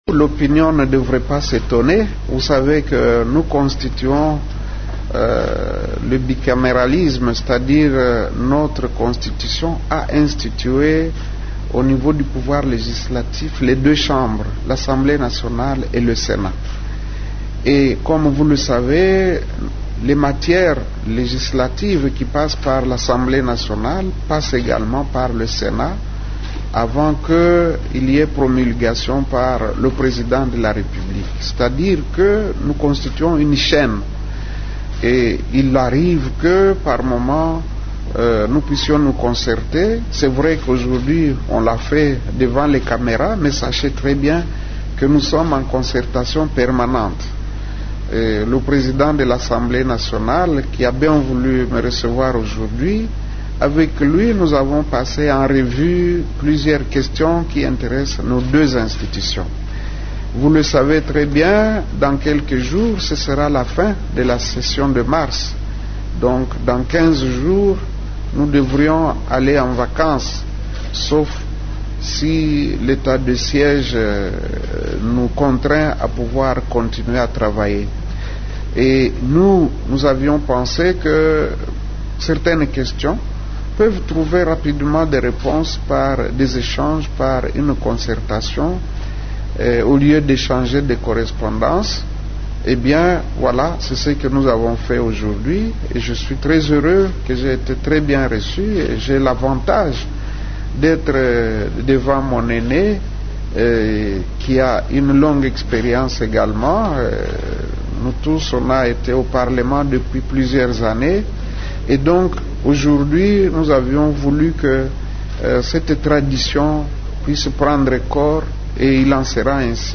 Suivez la déclaration du président du Sénat Modeste Bahati dans cet extrait :